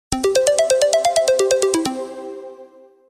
Index of /phonetones/unzipped/Lenovo/A6000/notifications
Flutey_Water.ogg